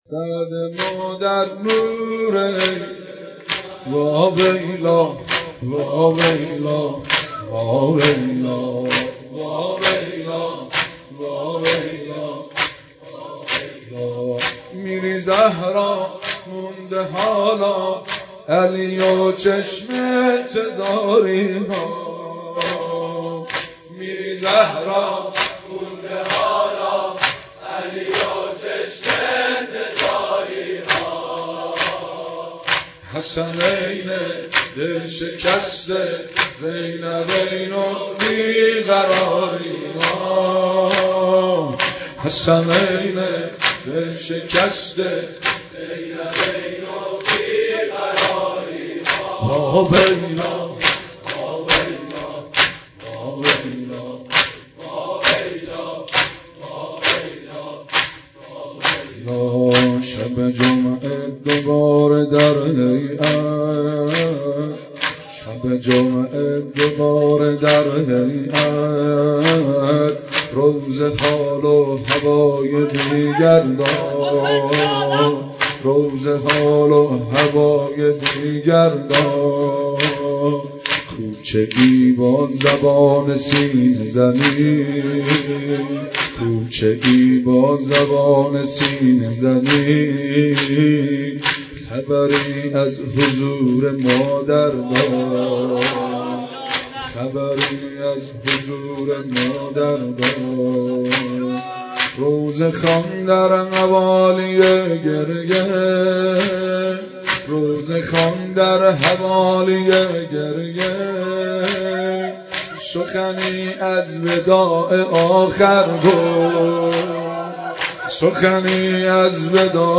سینه زنی در شهادت حضرت زهرا(س